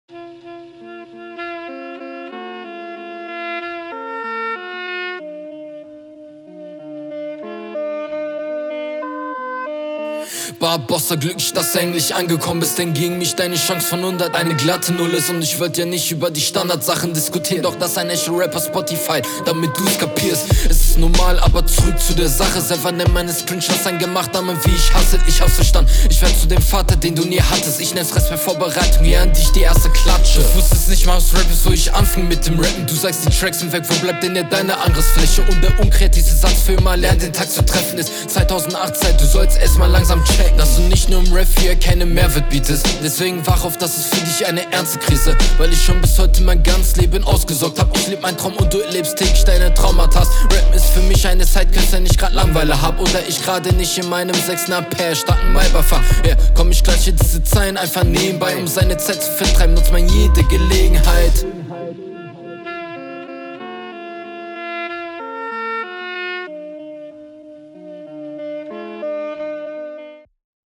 Ich mag die Aggressivität, aber die Audio tut fast weh, mach da mal was
Flow sehr unsicher und hektisch und teilweise offbeat.